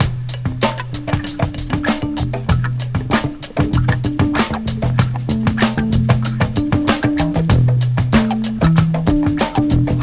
• 96 Bpm Fresh Breakbeat E Key.wav
Free drum loop sample - kick tuned to the E note. Loudest frequency: 773Hz
96-bpm-fresh-breakbeat-e-key-xhE.wav